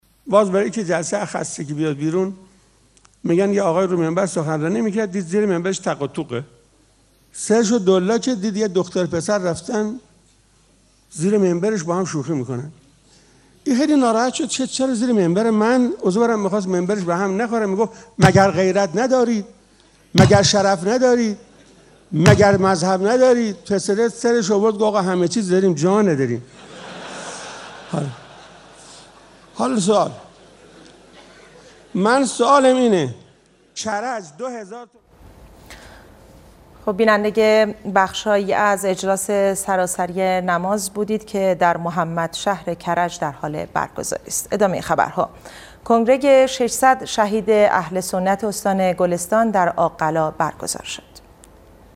خاطره جالب استاد قرائتی که منجر به قطع پخش زنده اجلاس سراسری نماز از شبکه خبر شد